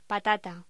Locución: Patata
voz